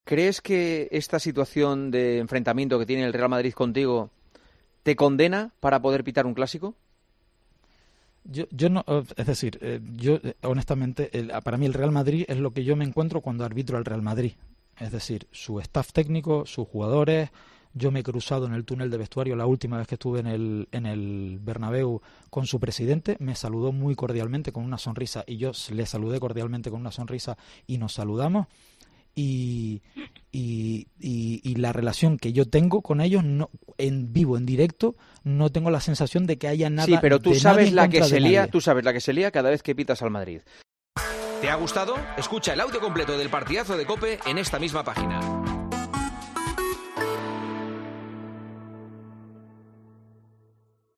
El colegiado repasó en El Partidazo de COPE junto a Juanma Castaño la situación del arbitraje y algunos temas como el Caso Negreira.